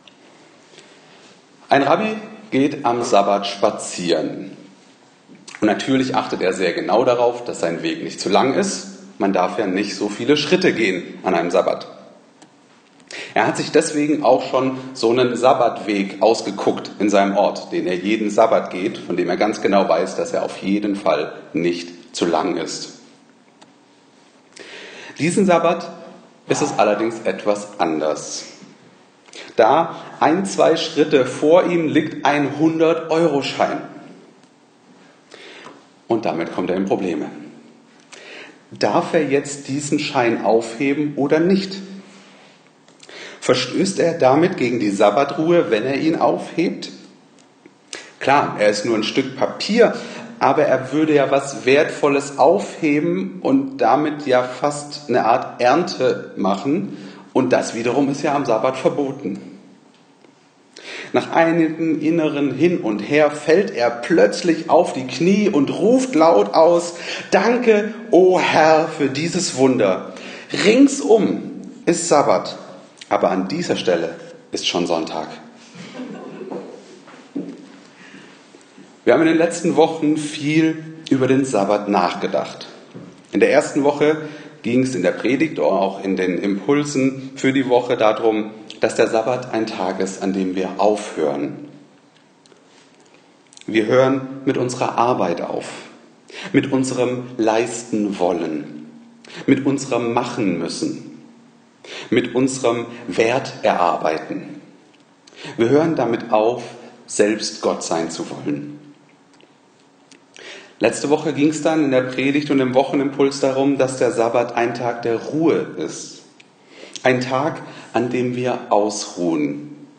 sabbat-predigt03.mp3